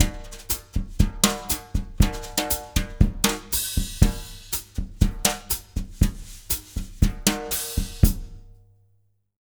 120BOSSA06-L.wav